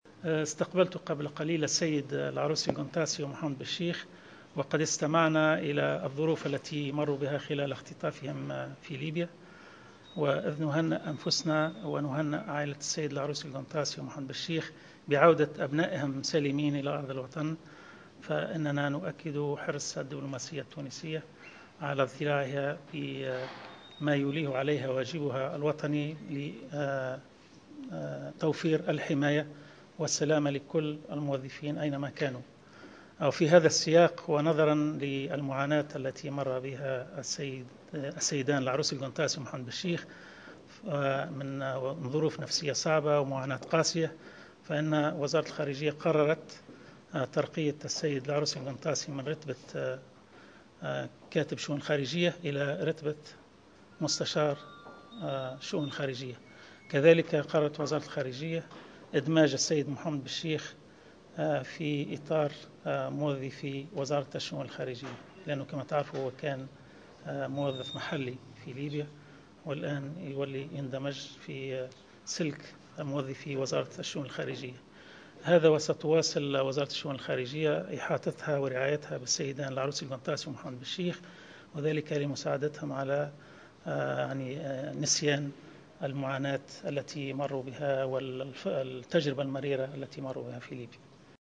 Dans une d�claration � Jawhara Fm, le ministre des Affaires �trang�res, Mongi Hamdi, a annonc�, mercredi 2 juillet 2014, la promotion des deux anciens otages tunisiens en Libye, lib�r�s dimanche dernier..